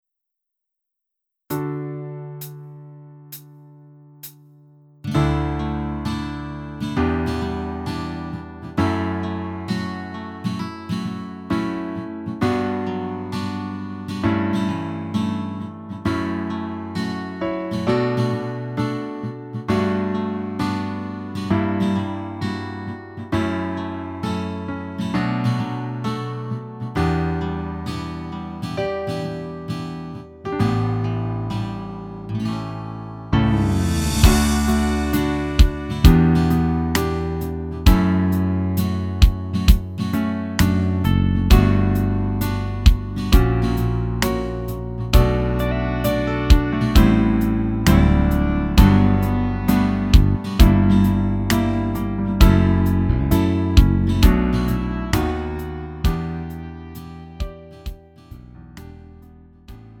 음정 -1키 4:12
장르 가요 구분